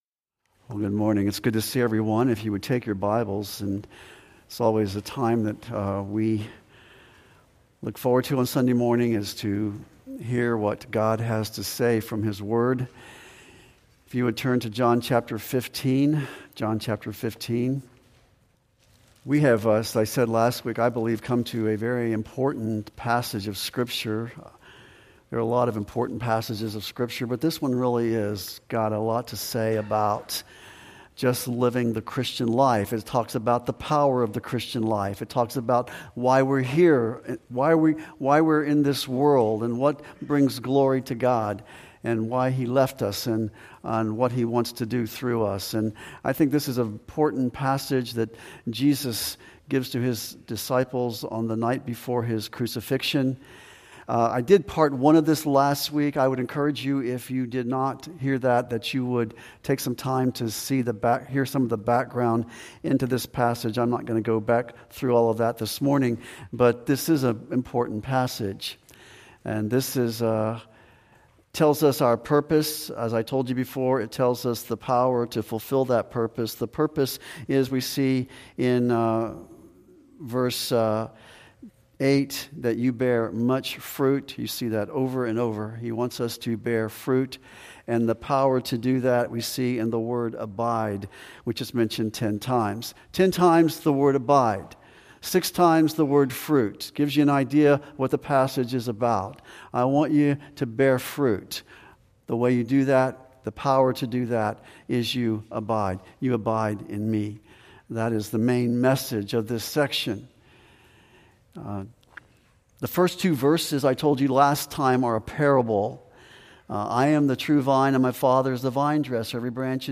2024-09-02-Sermon.mp3